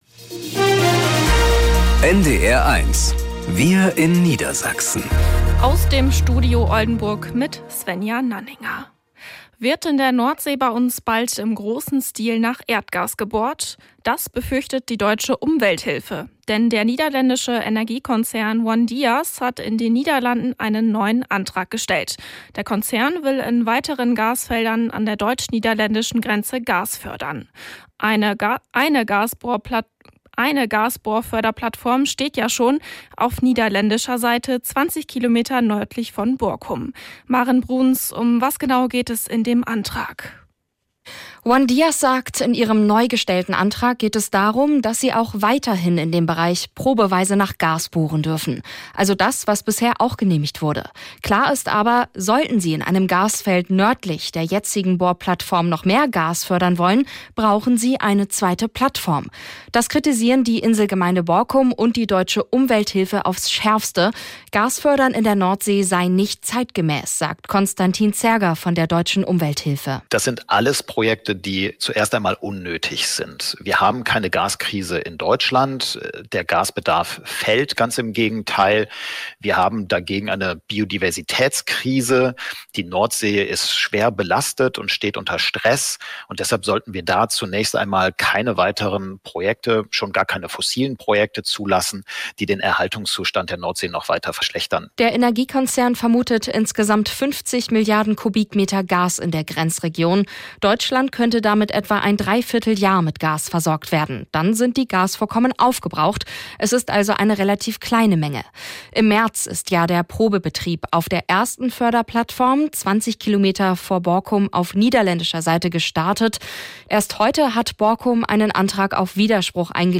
… continue reading 4 episodes # Tägliche Nachrichten # Nachrichten # NDR 1 Niedersachsen